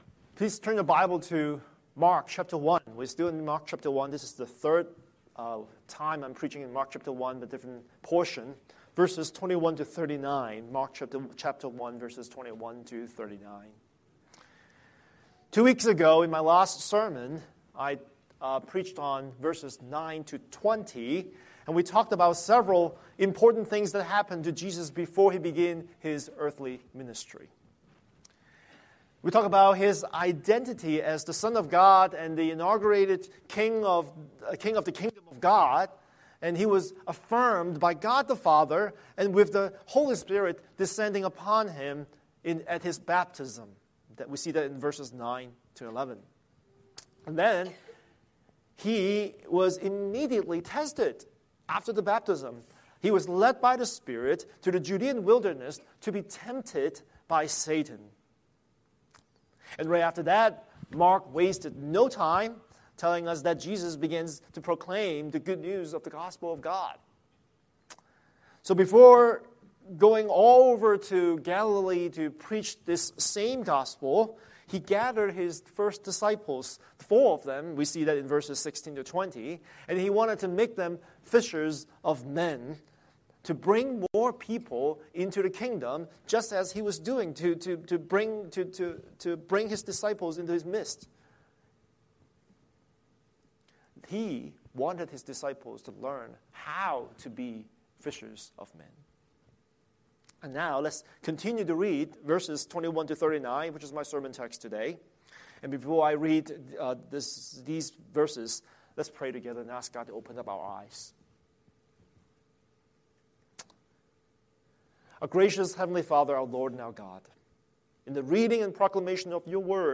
Scripture: Mark 1:21-39 Series: Sunday Sermon